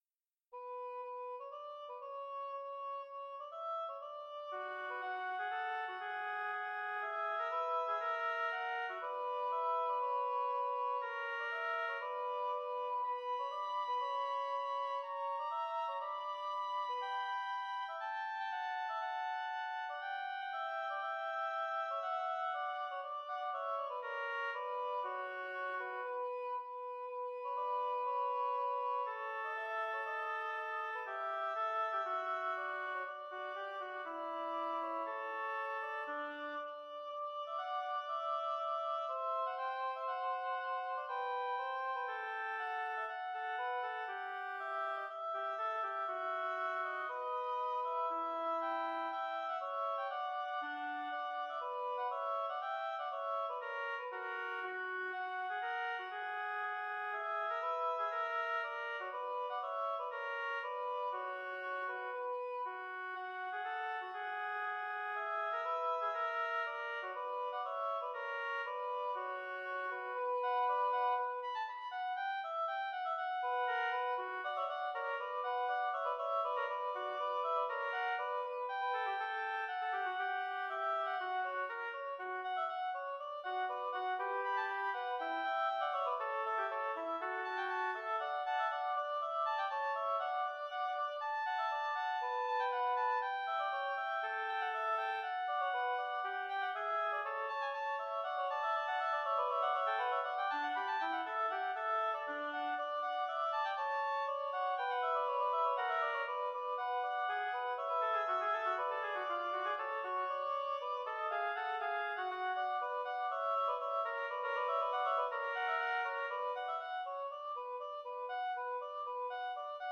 Intermediate oboe duet
oboe music